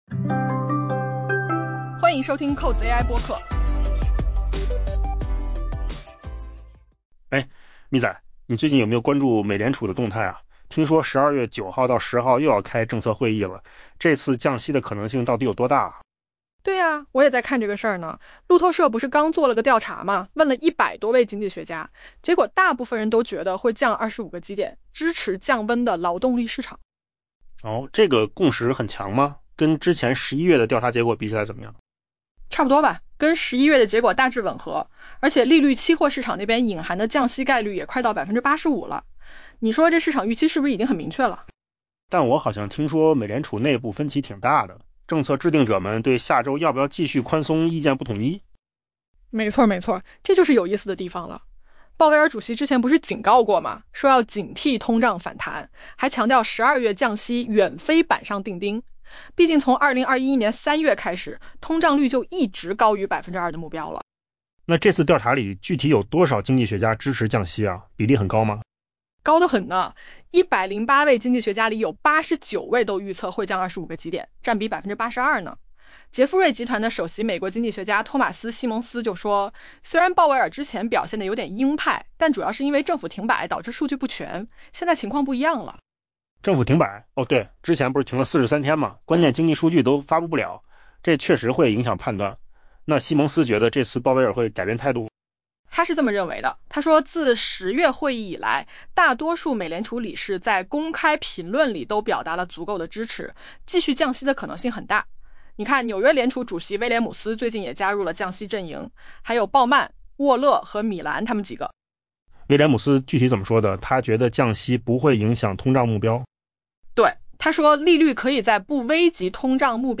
AI 播客：换个方式听新闻 下载 mp3 音频由扣子空间生成 根据路透社对 100 多位经济学家的调查，美联储将在 12 月 9 日至 10 日的政策会议上降息 25 个基点，以支持正在降温的劳动力市场。